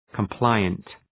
Προφορά
{kəm’plaıənt}
compliant.mp3